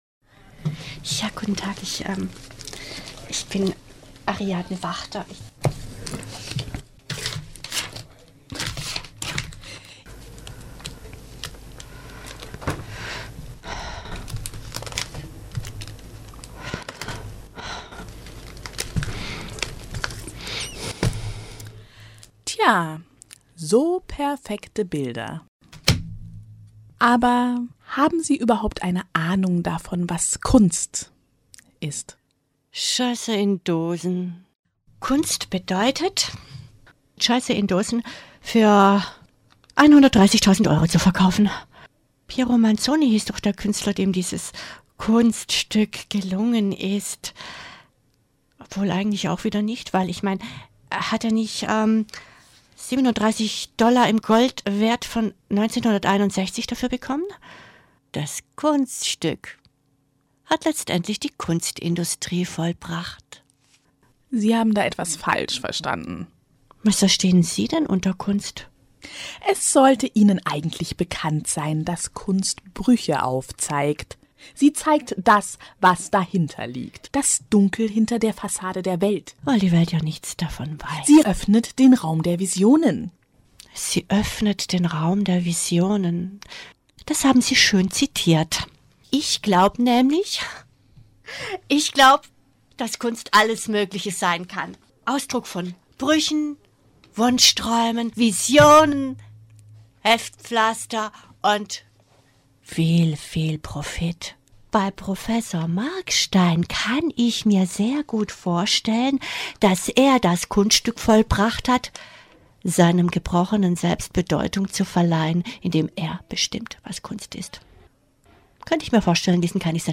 Hörspiel: "Das verlorene Leuchten"
Die Personen und ihre SprecherInnen: